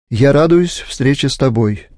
Dodatkowo program kształci rozumienie ze słuchu (10 000 nagrań rodowitych Rosjan), utrwala zasady gramatyki i ortografii, a opcja nagrywania przez mikrofon pozwala nabrać właściwego akcentu.